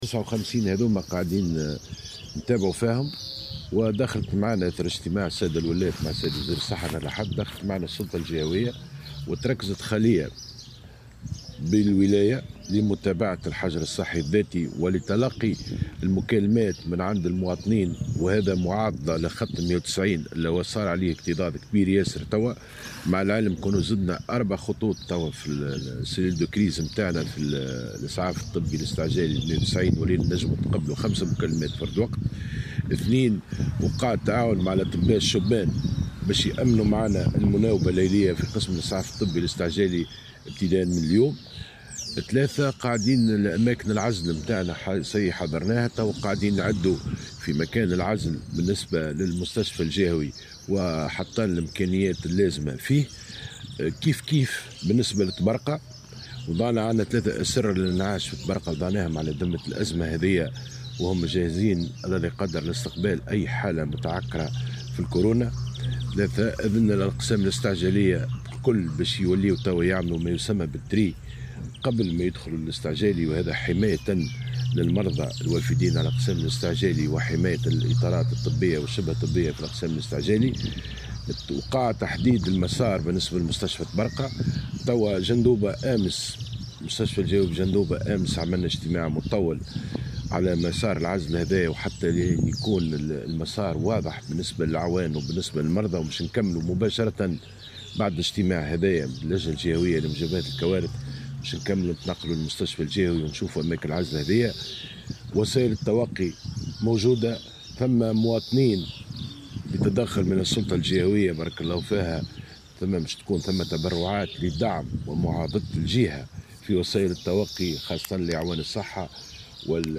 بلغ عدد الأشخاص الذين يخضعون للحجر الصحي المنزلي بولاية جندوبة 59 شخصا حسب ما أكده المدير الجهوي للصحة بجندوبة محمد رويس في تصريح لمراسل الجوهرة "اف ام".